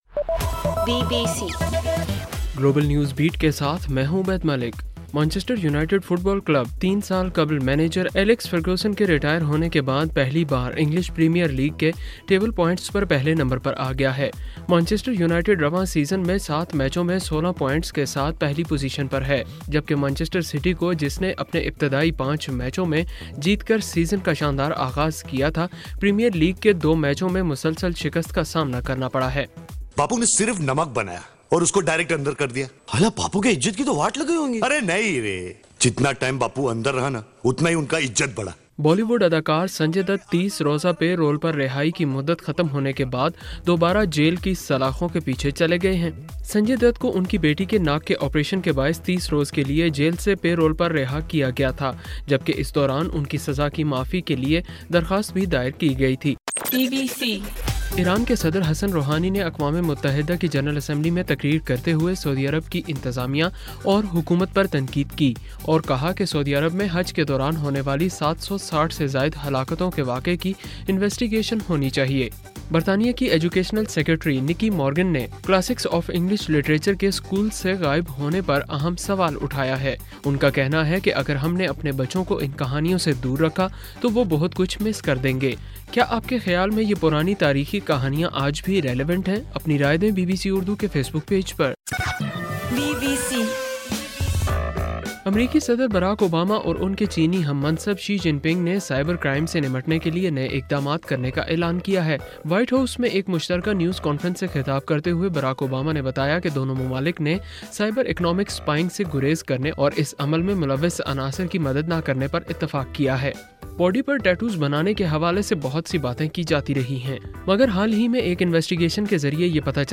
ستمبر 26: رات 12 بجے کا گلوبل نیوز بیٹ بُلیٹن